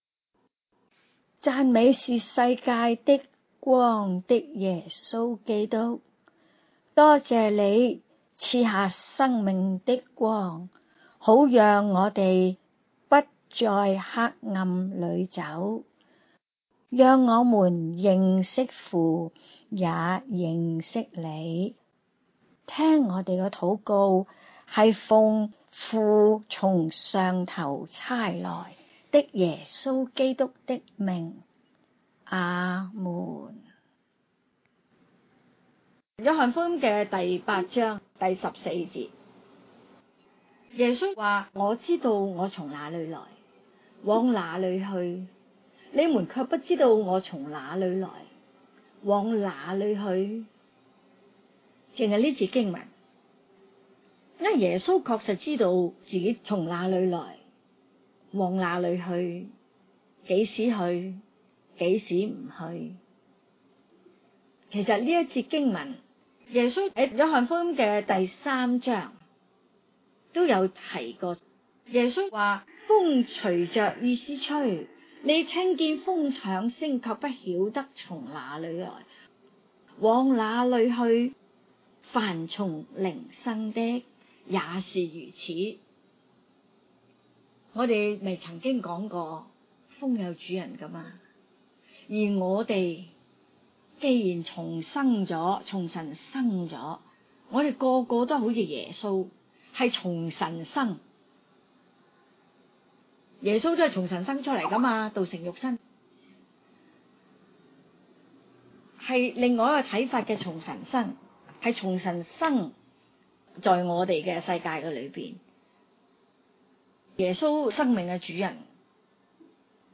請注意： 每段教導均以認識【上帝之所是】和貫穿新舊約的重點信息－【上帝全備救贖計劃】如何安排的心意，作為明白該段經文的基礎； 若在學習中，要得到更好的果效，請在聆聽每段教導前，先【用心讀】該段經文最少兩次，然後專注在心靈裡來聆聽數次； 每段聲帶均按經文的章節次序來教導，故有些內容並不是局限於一個重點或主題來闡明； 每段教導的「聲帶」均為現場錄音，就部份或有雜音及音效未盡完善的地方，敬請包容！